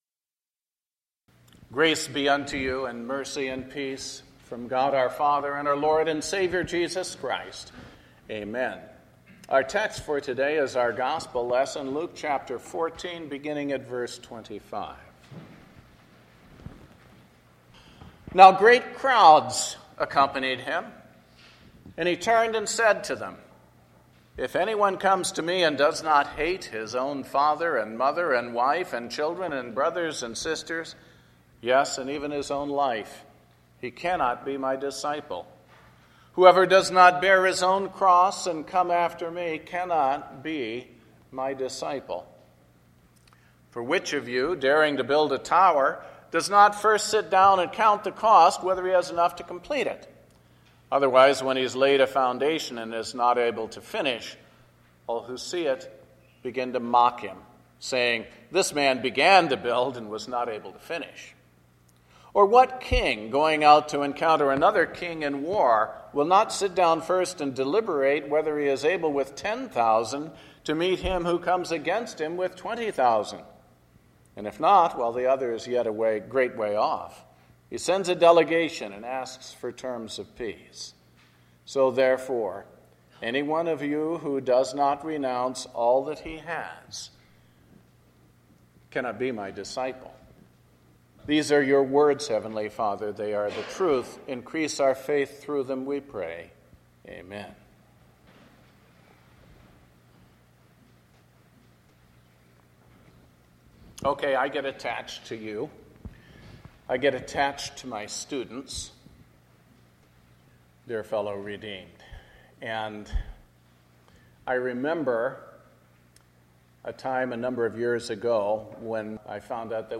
The Fourth Sunday After Pentecost – Sermon based on Luke 7:41-50. (Father’s Day)